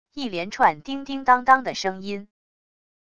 一连串叮叮当当的声音wav音频